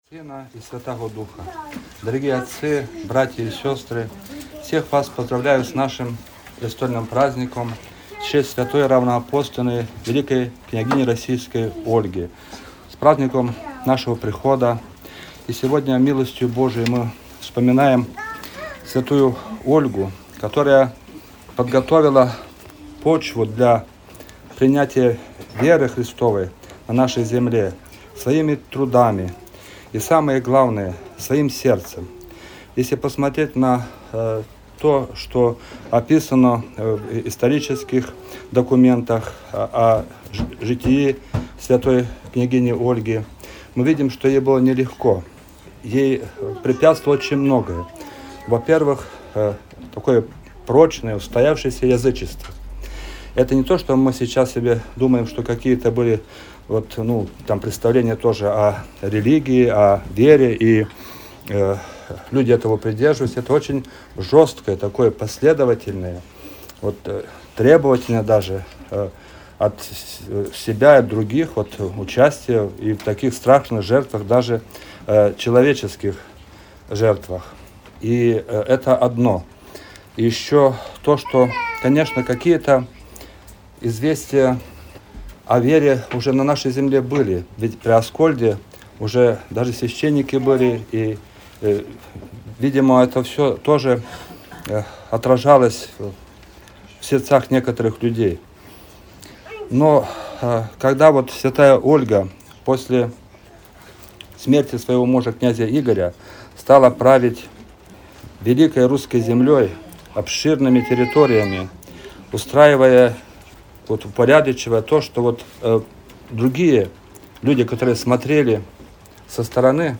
Проповедь-в-день-памяти-св.-княгини-Ольги.mp3